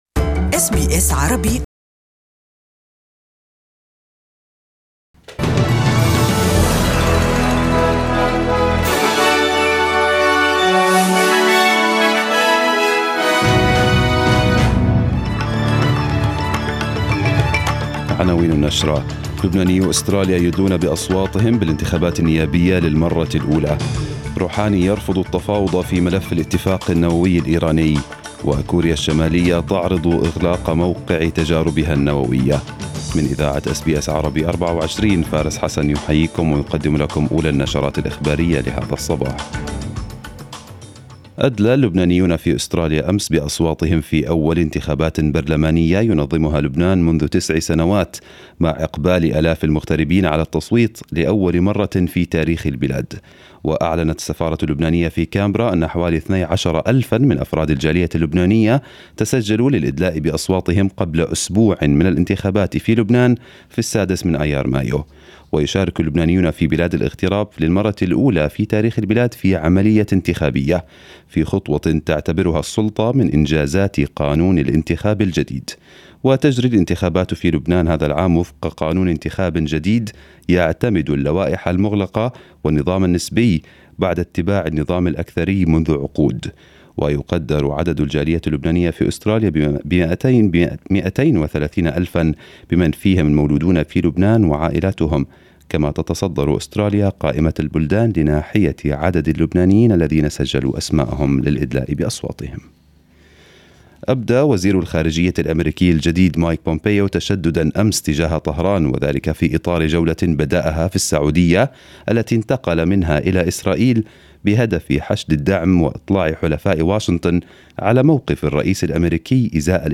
Arabic News Bulletin 30/04/2018